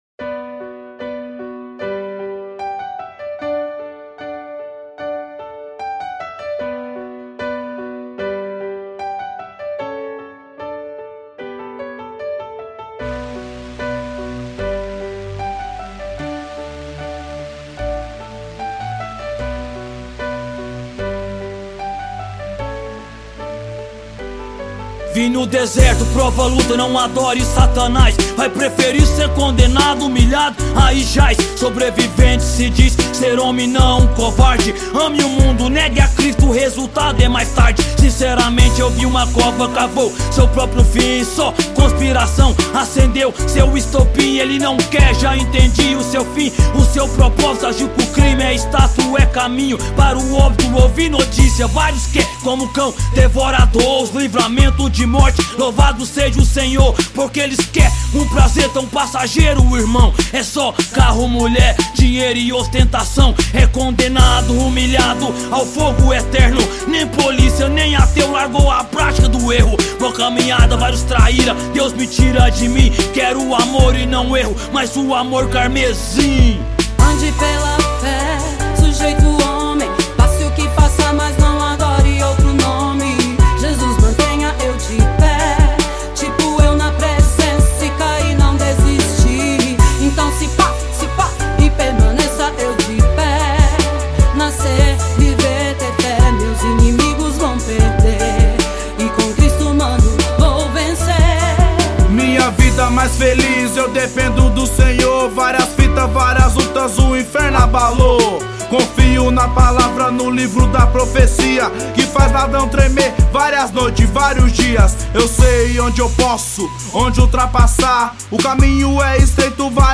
Composição: rap gospel.